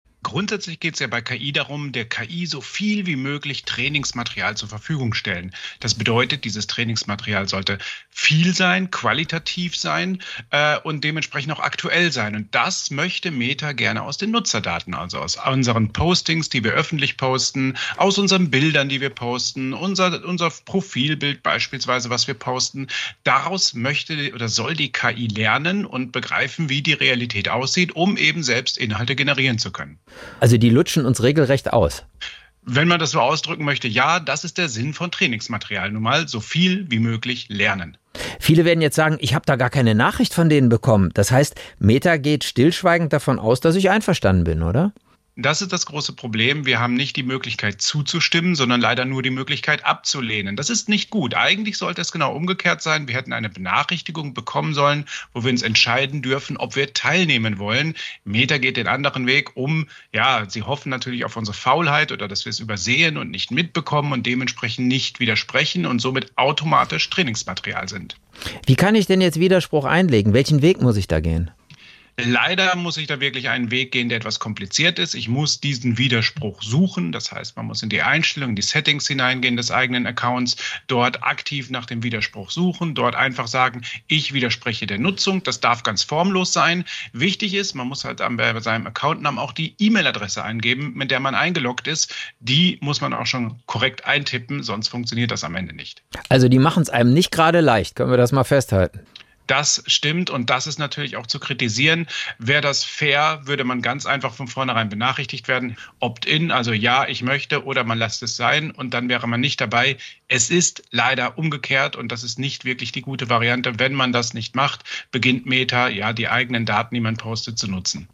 Nachrichten Widerspruch bei Meta – das müsst ihr wissen